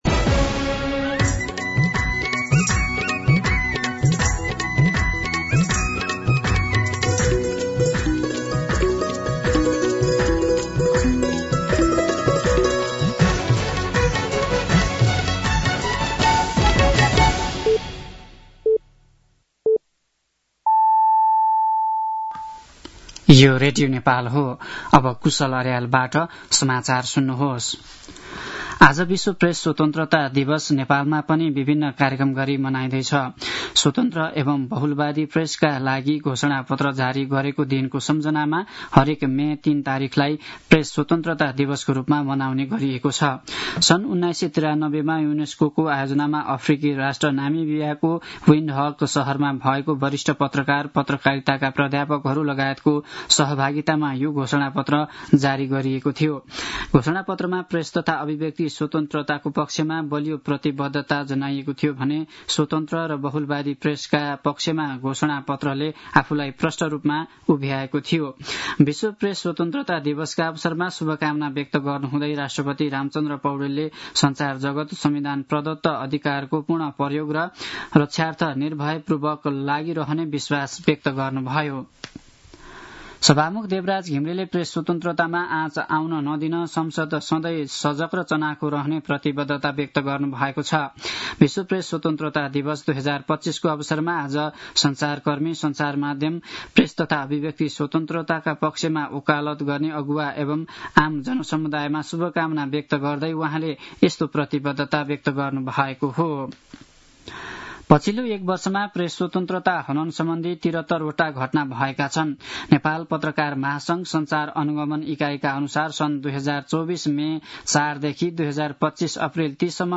दिउँसो १ बजेको नेपाली समाचार : २० वैशाख , २०८२